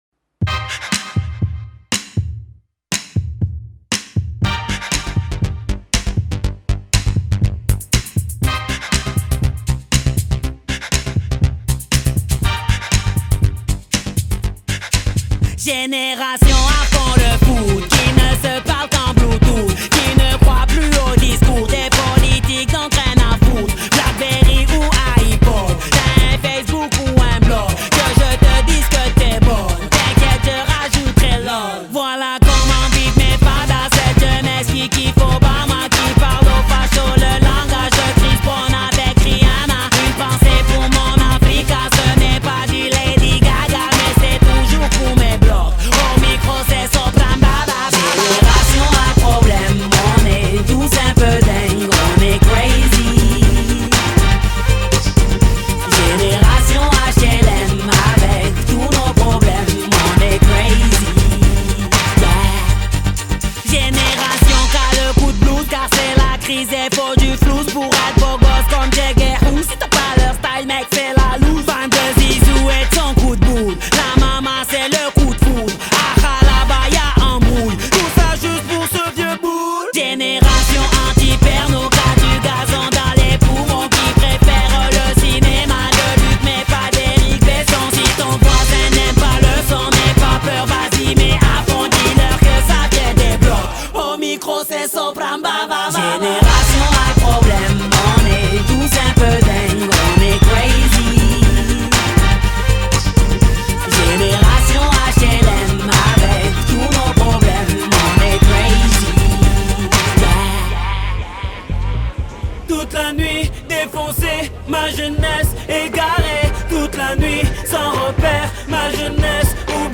Genre: Metal